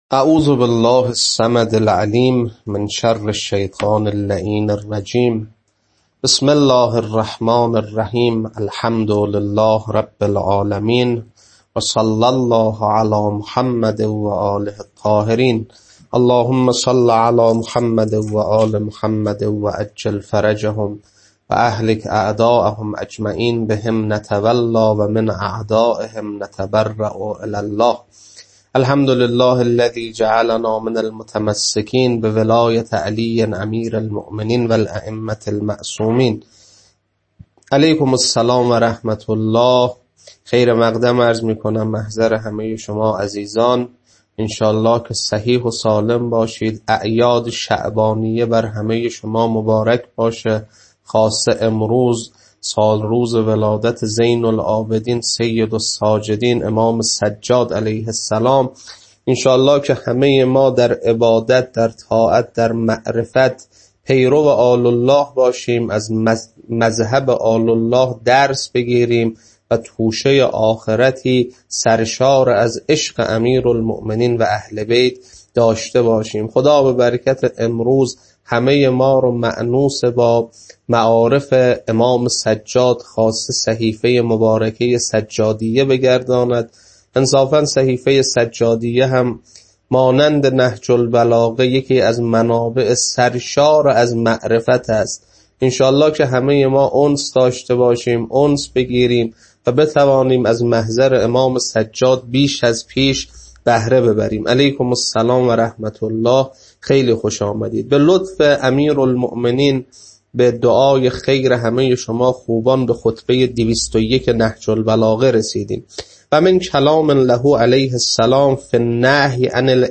خطبه-201.mp3